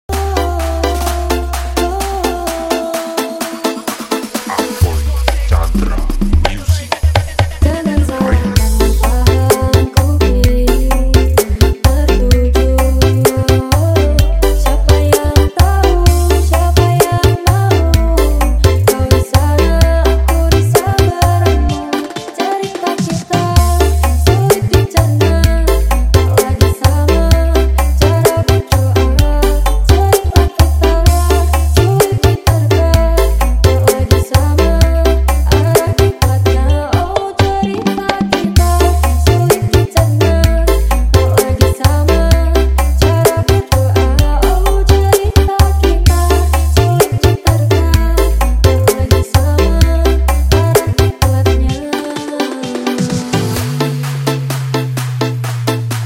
KARNAVAL SOUND SYSTEM